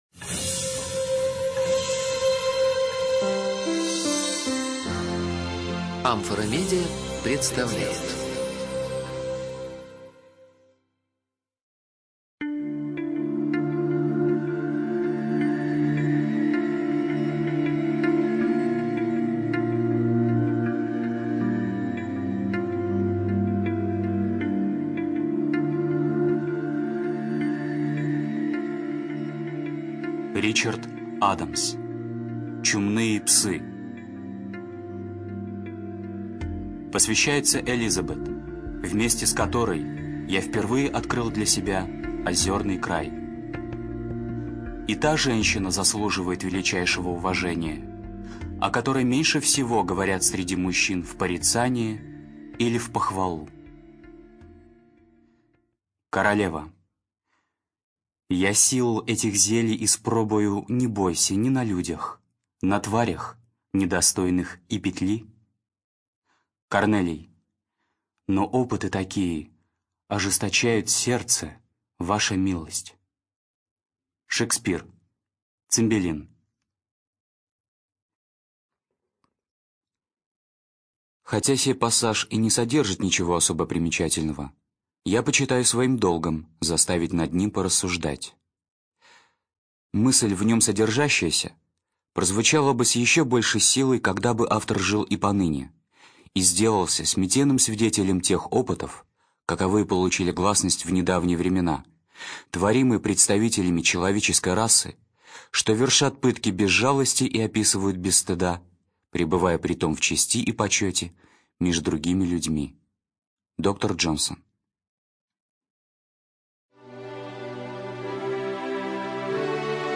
Студия звукозаписиАмфора